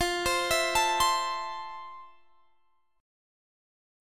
FM7 Chord
Listen to FM7 strummed